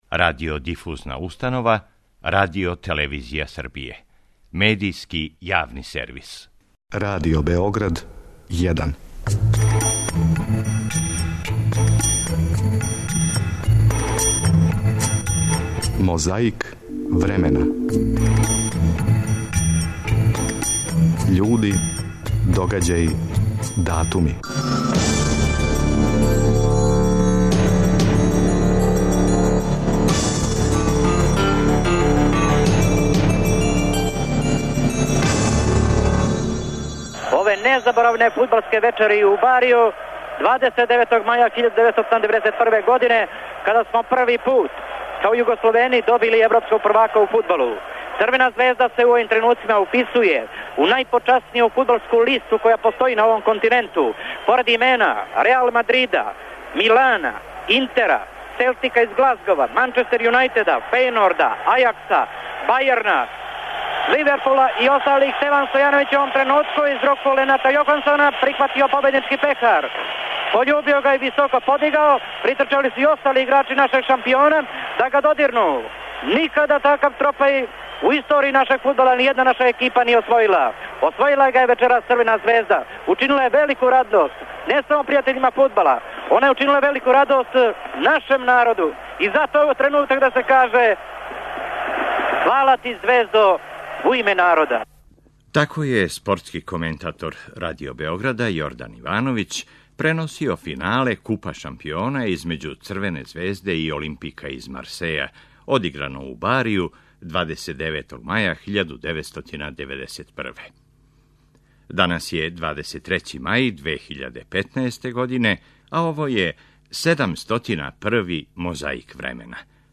Касније је тај датум проглашен за Дан младости са слетом штафетом и другим украсима.